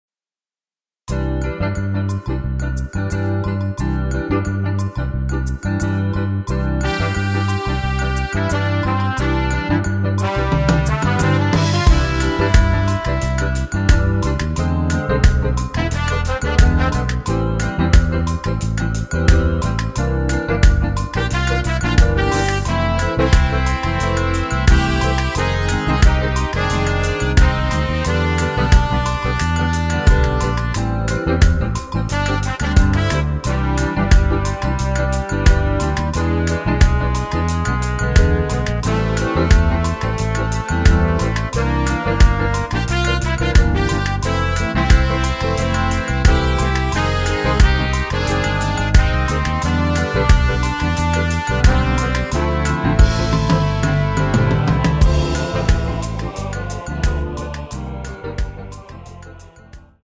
원키에서(-4)내린 MR입니다.
엔딩이 길어 축가에 사용 하시기 좋게 엔딩을 짧게 편곡 하였습니다.(원키 코러스 버전 미리듣기 참조)
앞부분30초, 뒷부분30초씩 편집해서 올려 드리고 있습니다.
중간에 음이 끈어지고 다시 나오는 이유는